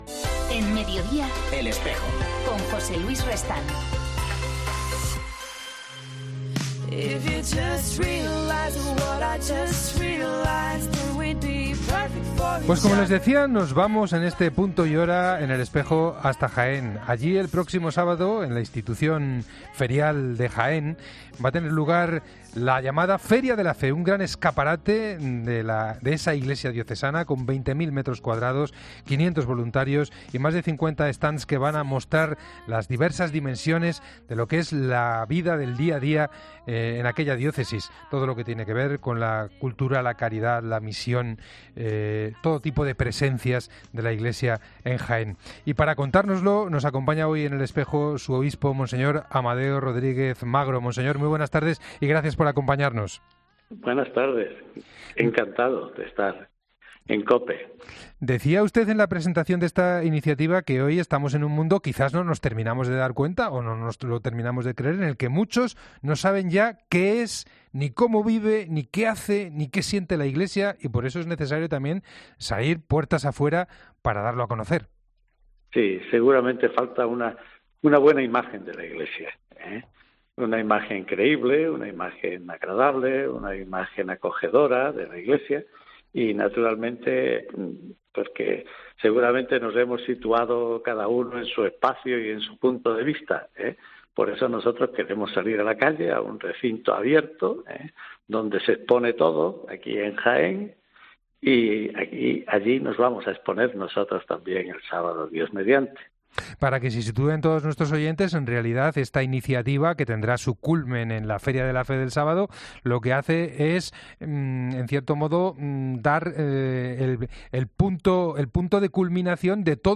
El obispo de Jaén, Mons. Amadeo Rodríguez Magro, explica en El Espejo  esta iniciativa que se celebra el próximo sábado 19 de octubre.